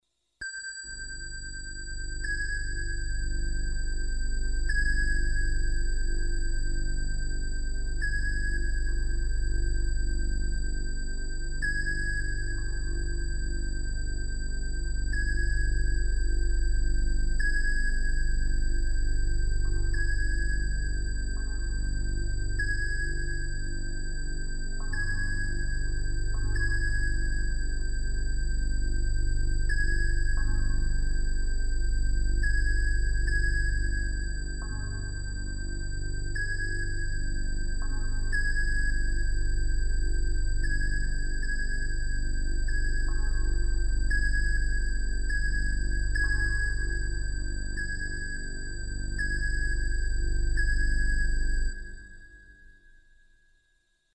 listen to my tinitus
It is sometimes referred to 'ringing in the ears' - that never stops.
If you have speakers connected to your PC  then click the link on the left column to hear the sound I hear perpetually.
tinitus.mp3